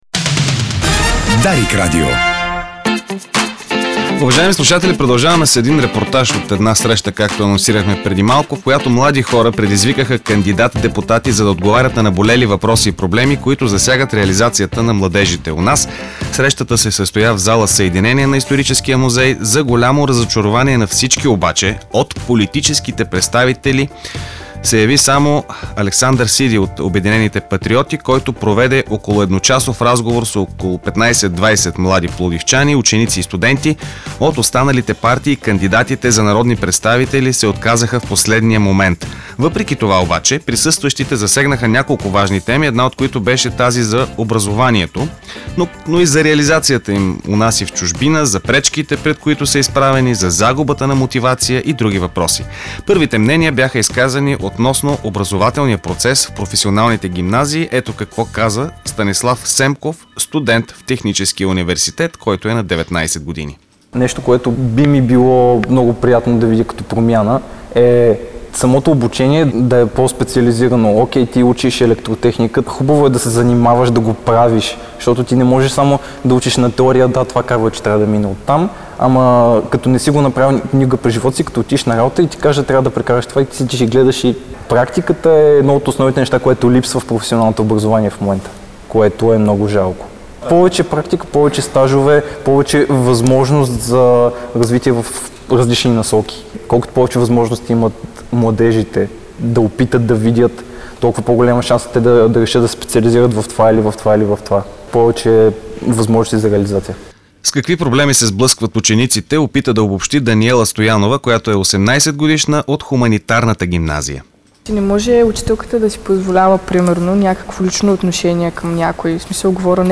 Млади хора предизвикаха кандидат-депутати да отговорят на наболели въпроси и проблеми, засягащи реализацията им. Срещата се проведе в зала „Съединение“ на Историческия музей в Пловдив.
За голямо разочарование на всички обаче, от политическите представители се яви само един, който проведе едночасов разговор с около 15 млади пловдивчани – ученици и студенти.